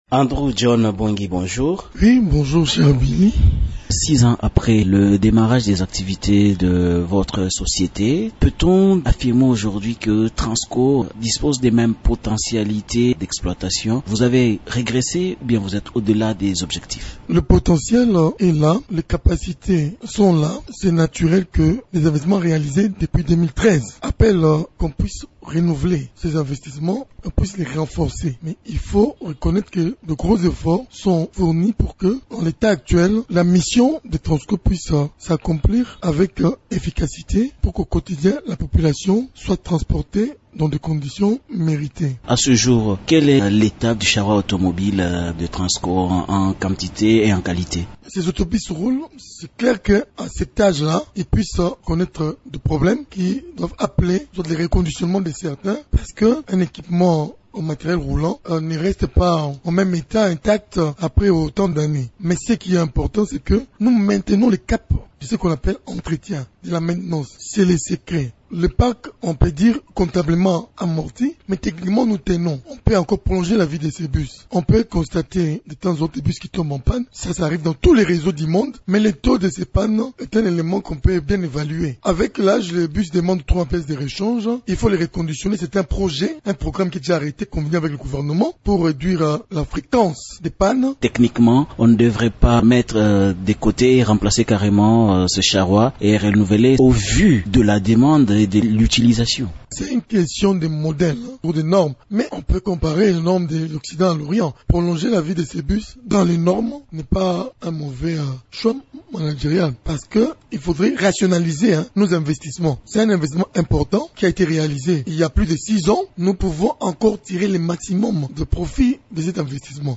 Dans une interview accordée à Radio Okapi, il rappelle aux chauffeurs que leur mission est de transporter la population en toute sécurité.